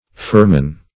Firman \Fir"man\ (? or ?), n.; pl. Firmansor. [Pers.